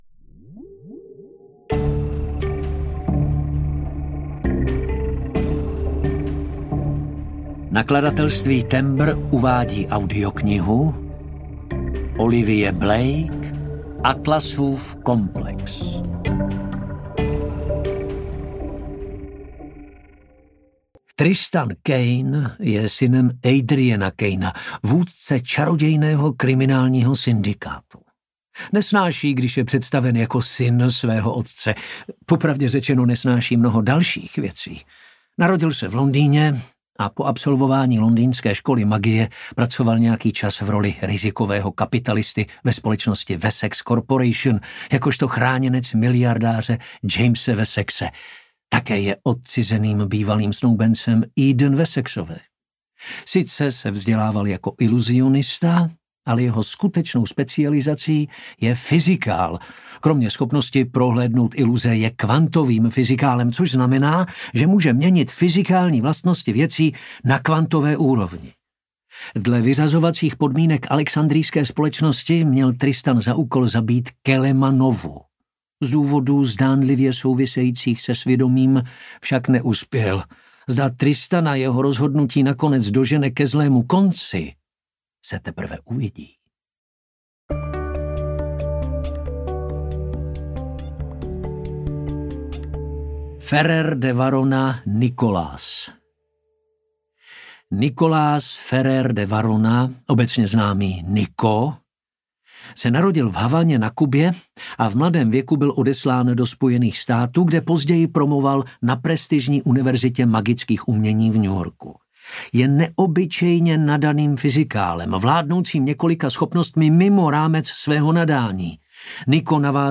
Atlasův komplex audiokniha
Ukázka z knihy